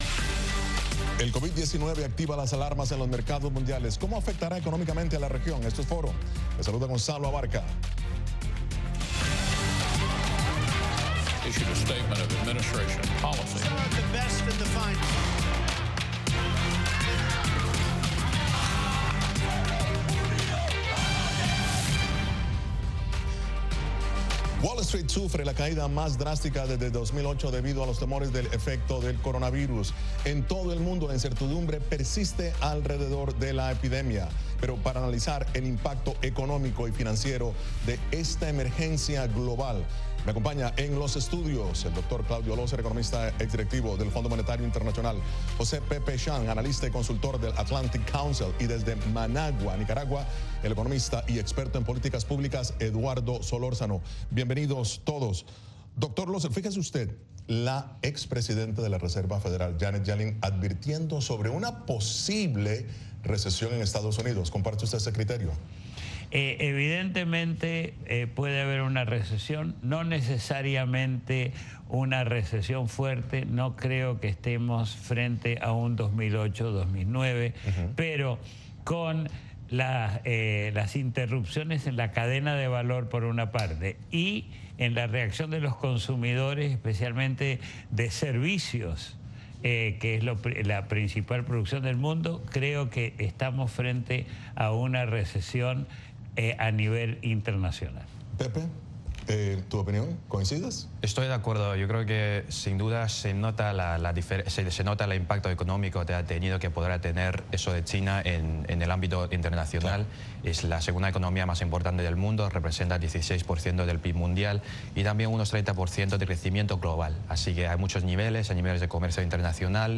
Programa de análisis de treinta minutos de duración con expertos en diversos temas.